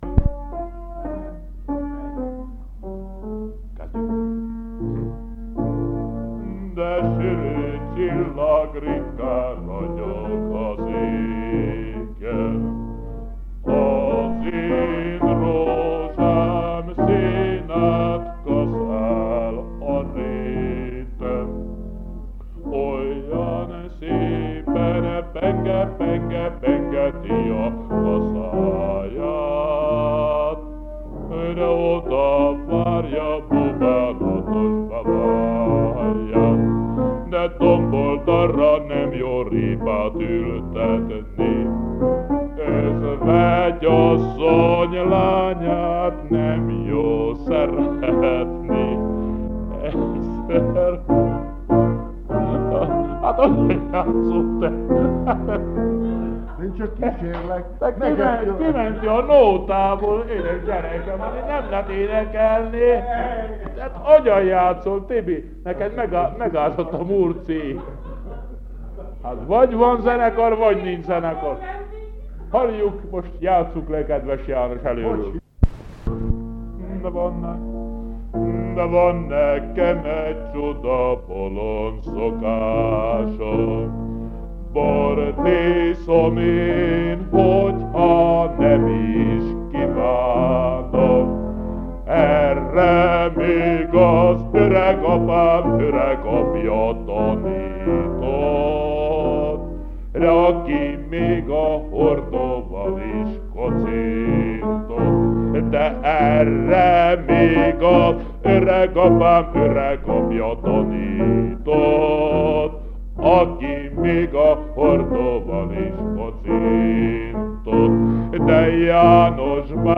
Оцифрованная лента от катушечного магнитофона
Звуковая запись 1962 года (магнитофон Terta-811)